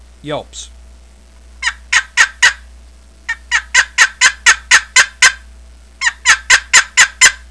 An Outrageously Loud Box Call
• Produces extra loud, keen, raspy notes totally different from conventional boxes
• Excellent yelps, clucks, and superb cutts at all volumes, especially loud
southlandscreaminyelps8.wav